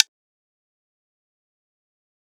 KK - Stick Perc.wav